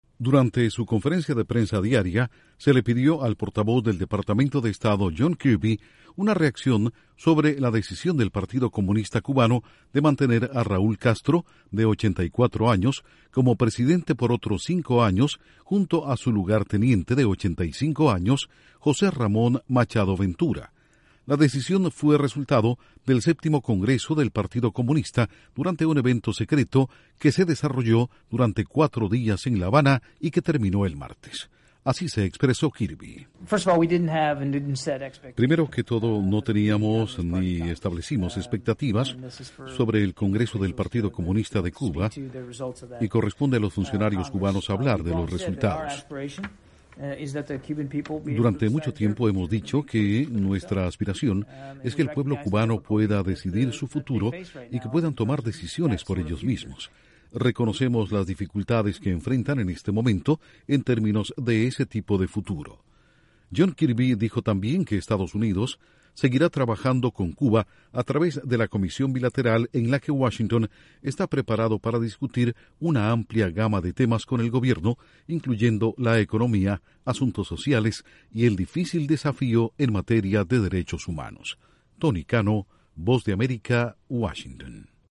Estados Unidos no tenía expectativas sobre el resultado del congreso del partido comunista de Cuba. Informa desde la Voz de América en Washington